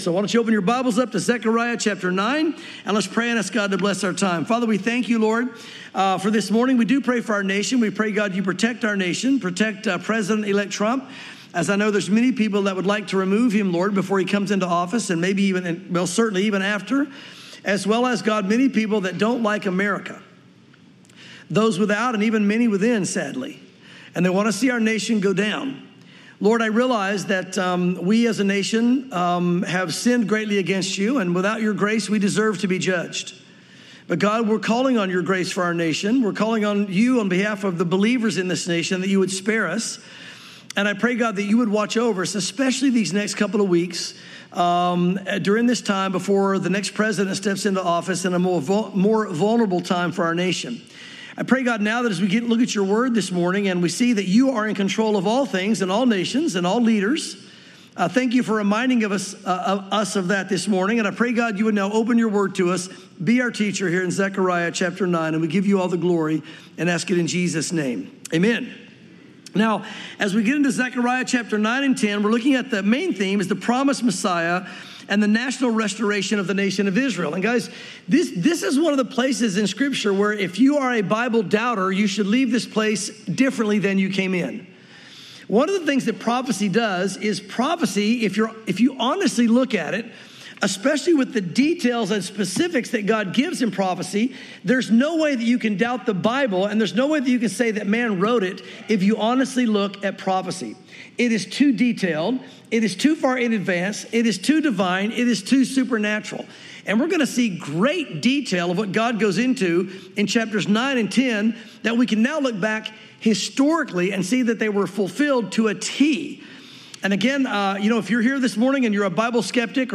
sermons Zechariah 9 & 10 | The Promised Messiah / National Restoration